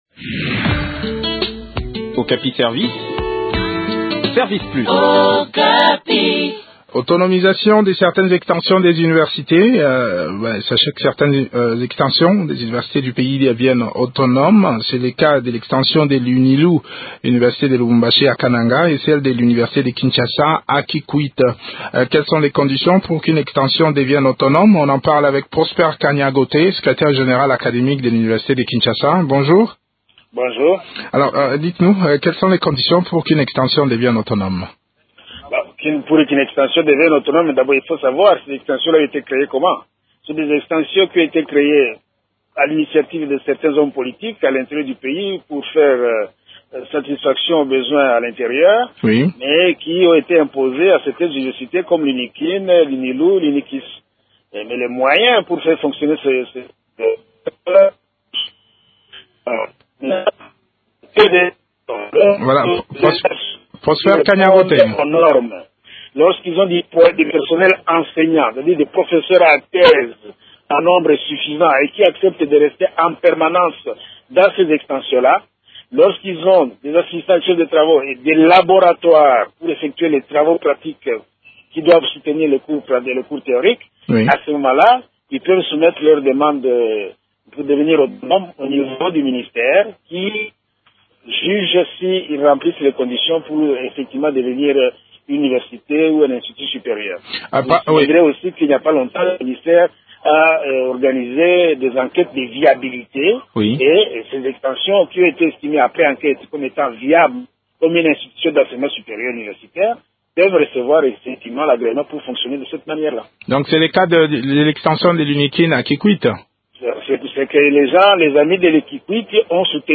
s’entretient sur le sujet avec